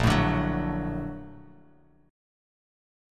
A#+M7 chord